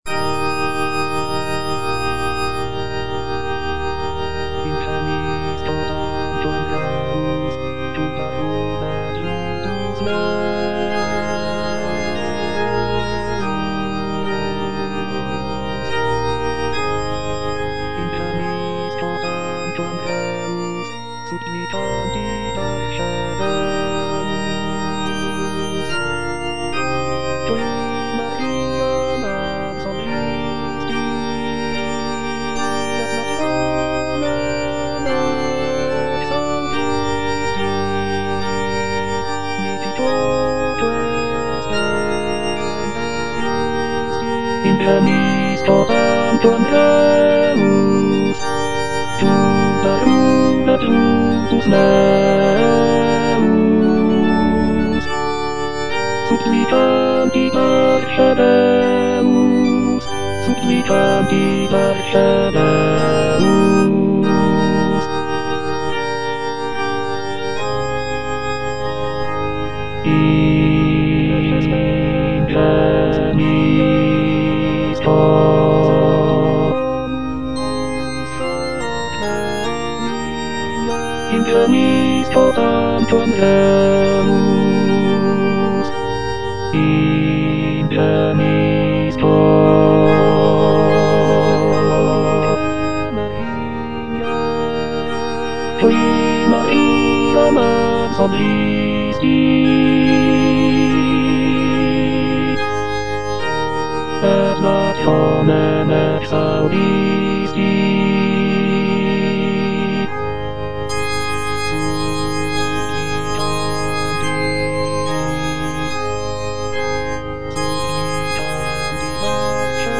(bass I) (Emphasised voice and other voices) Ads stop
is a sacred choral work rooted in his Christian faith.